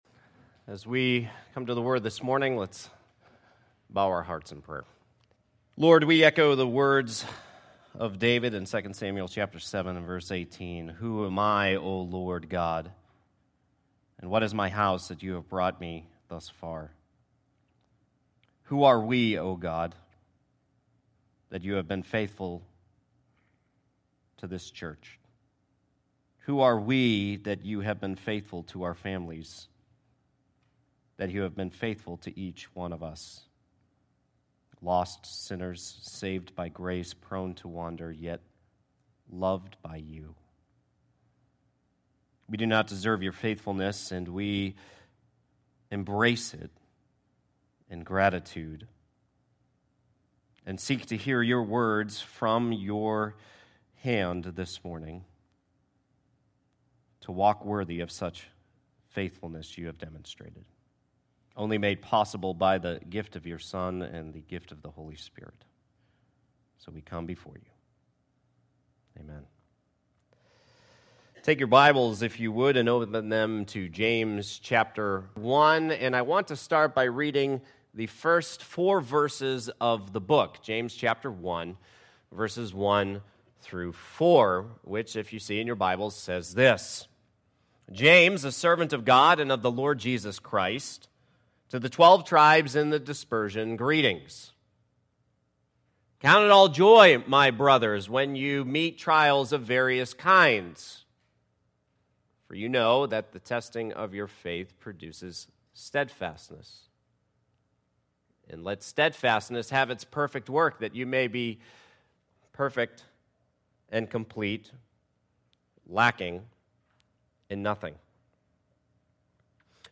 Sermons | Gospel Life Church